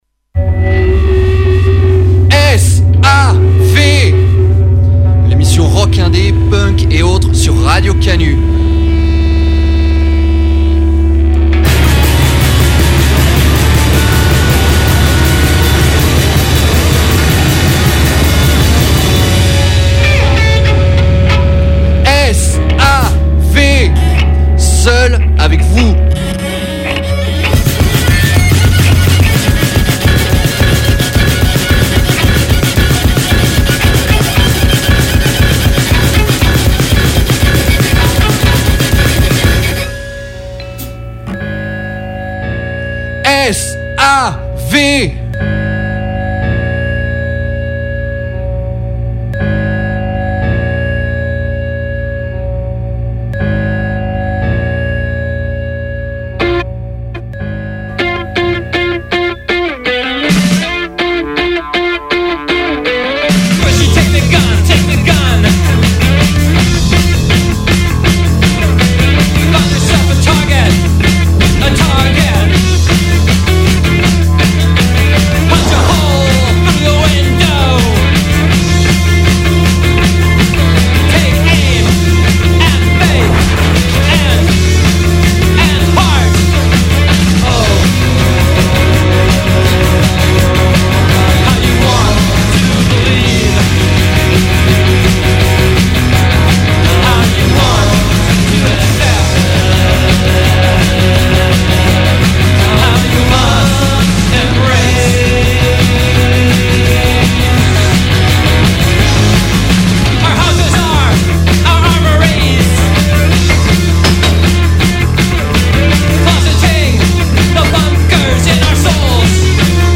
Une sélection de nouveautés punk/indie/hardcore !